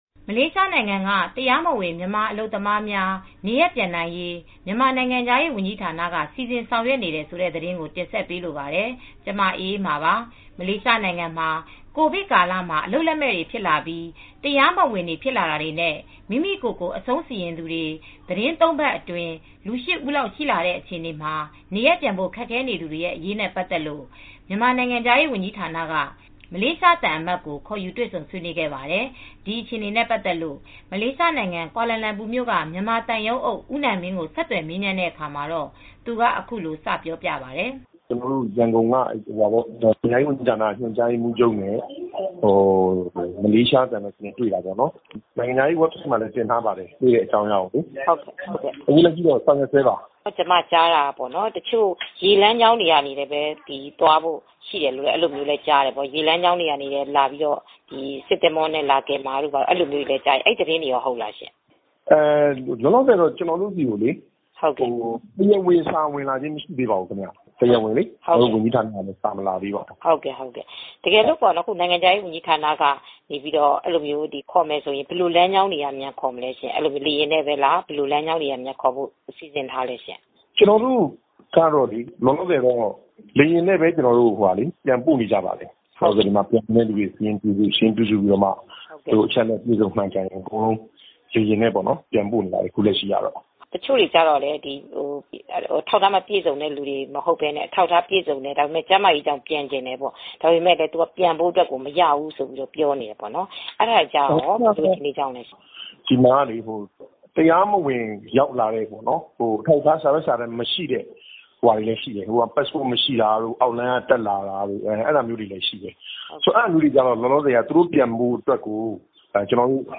ဒီအခွအေနတှေနေဲ့ ပတျသကျလို့ မလေးရှားနိုငျငံ ကှာလာလမျပူမွို့က မွနျမာသံရုံးအုပျ ဦးနိုငျမငျးကို ဆကျသှယျမေးမွနျးတဲ့အခါမှာ သူကအခုလို စ ပွောပါတယျ။